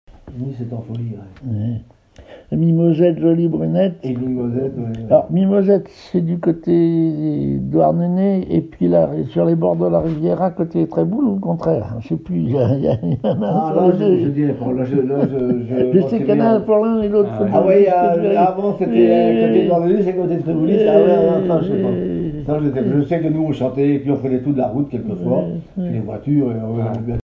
Enquête Douarnenez en chansons
Catégorie Témoignage